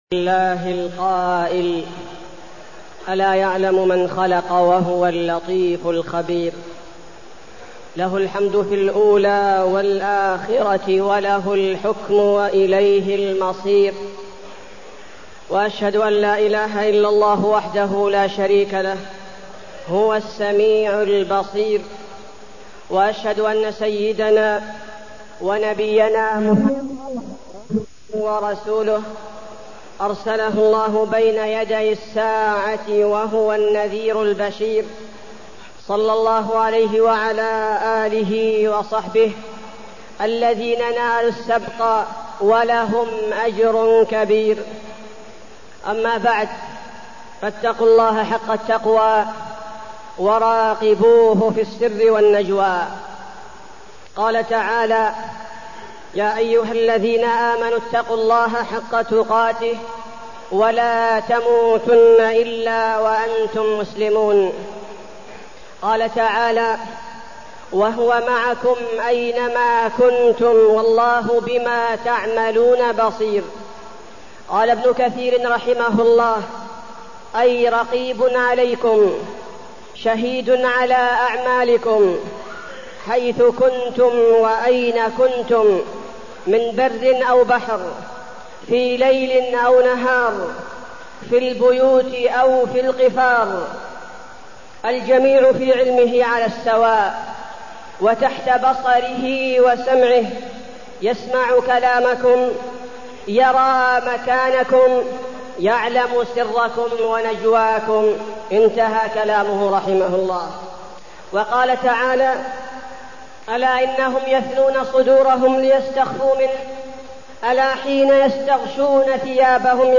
تاريخ النشر ٢٨ جمادى الآخرة ١٤٢٠ هـ المكان: المسجد النبوي الشيخ: فضيلة الشيخ عبدالباري الثبيتي فضيلة الشيخ عبدالباري الثبيتي مراقبة الله